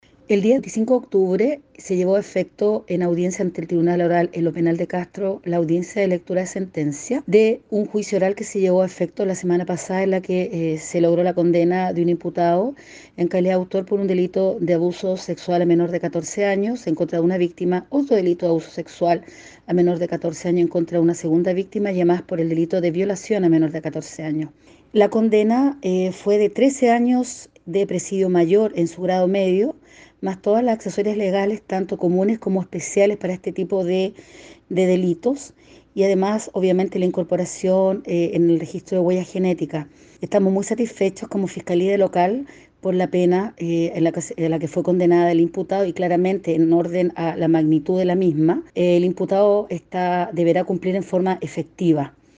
La fiscal Karyn Alegría se refirió a la sentencia comunicada en la que se impuso la pena de 13 años al acusado.